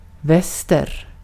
Ääntäminen
IPA: /vɛster/